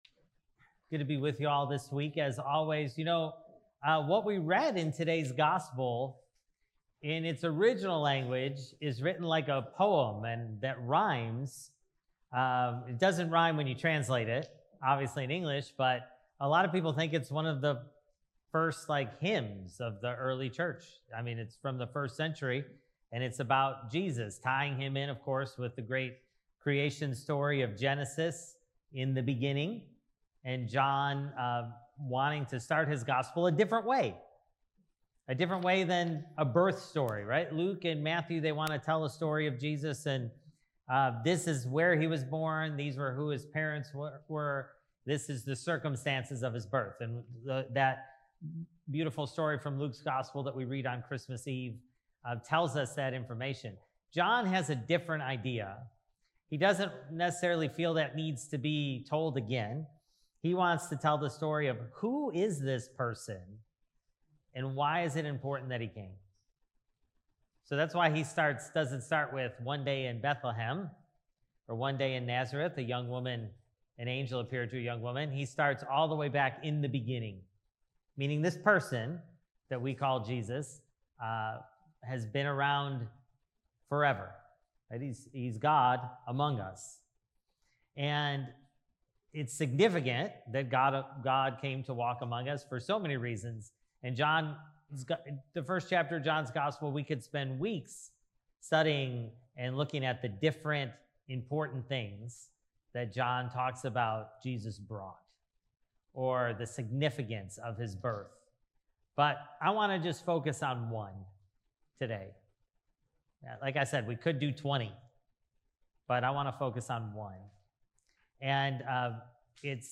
Sermon: Jesus Came to Show Us What God is Like (John 1:1-18)